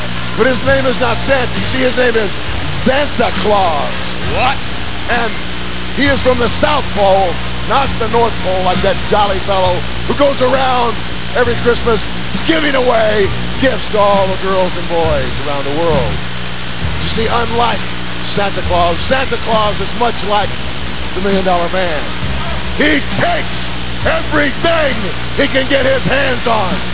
DiBiase went on Raw the next night to explain the actions of his evil St. Nick in one of the lamest, most idiotic storylines ever created. (Apologies for the bad audio.)